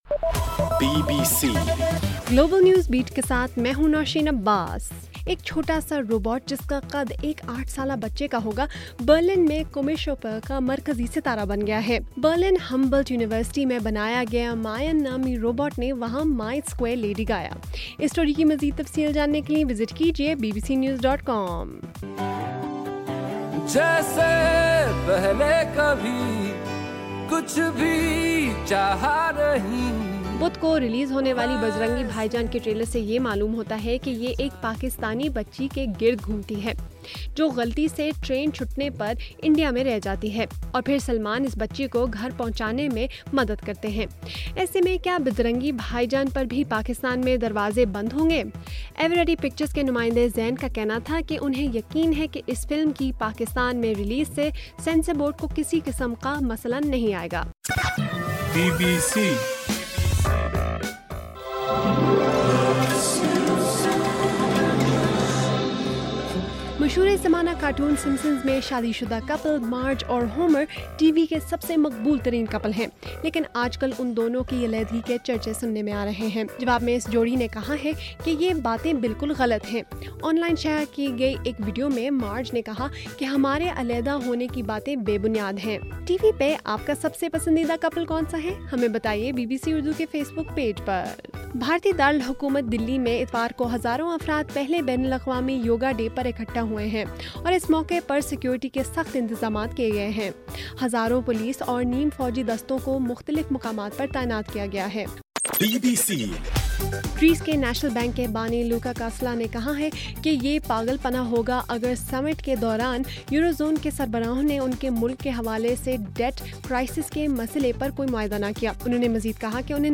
جون 21: رات 10 بجے کا گلوبل نیوز بیٹ بُلیٹن